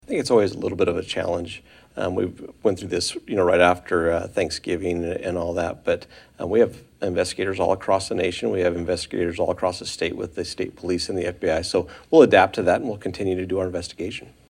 Fry says continuing the investigation while students head home for the Holidays will be a challenge.